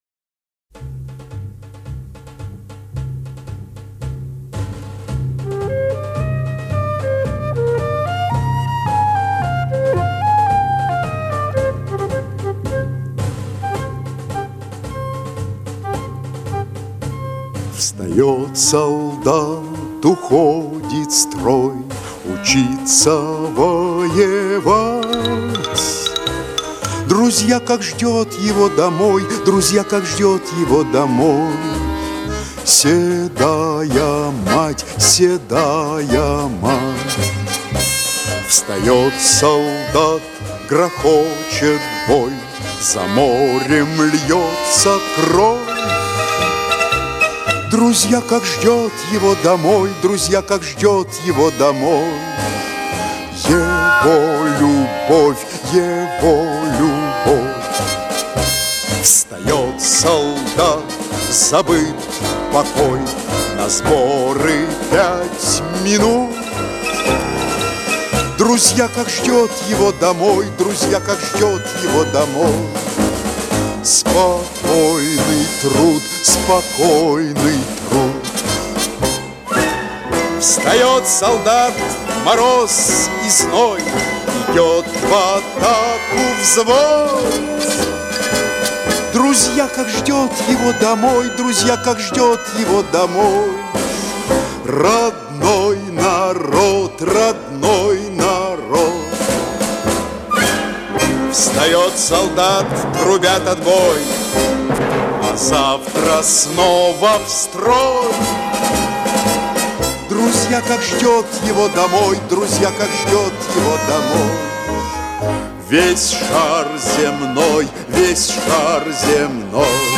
Eще одна "армейская" песня